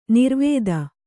♪ nirvēda